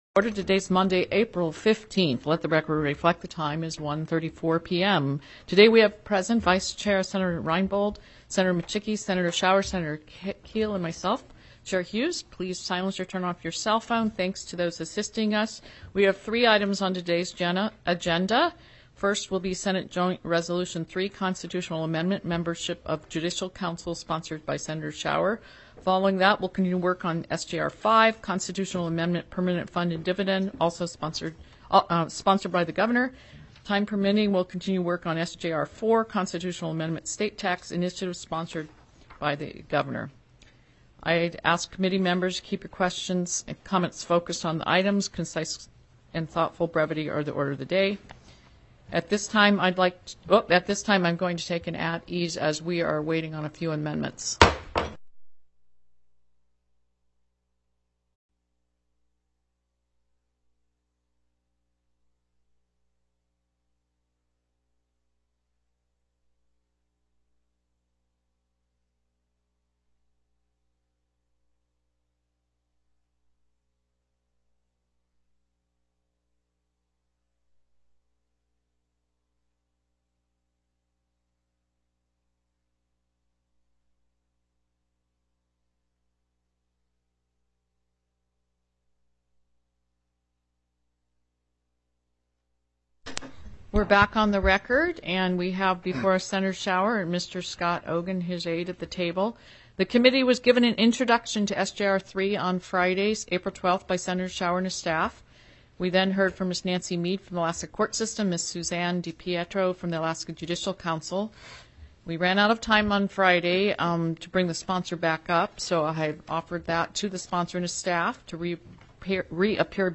The audio recordings are captured by our records offices as the official record of the meeting and will have more accurate timestamps.
AM: MEMBERSHIP OF JUDICIAL COUNCIL TELECONFERENCED Heard & Held -- Public Testimony -- += SJR 4 CONST.